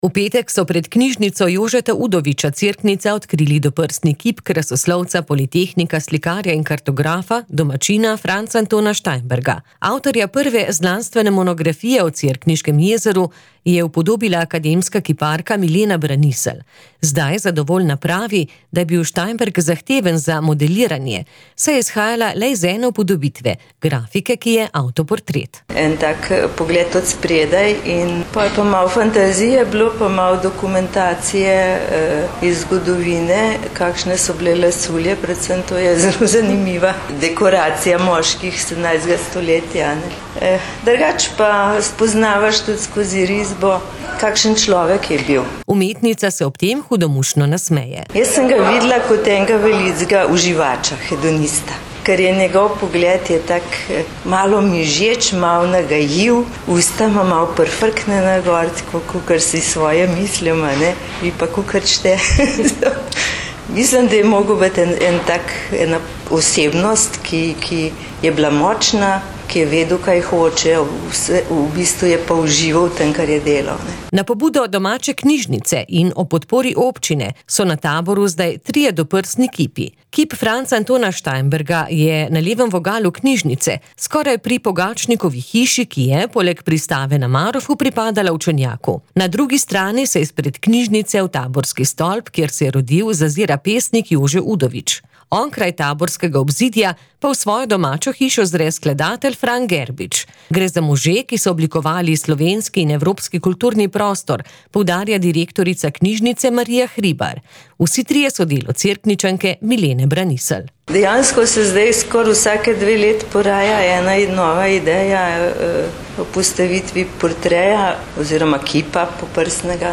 Povabili smo jo pred mikrofon.